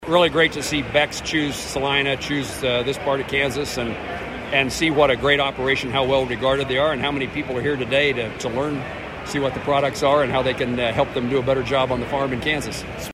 The gathering attracted U.S. Senator Jerry Moran who tells KSAL News, it’s another big win for the Salina area.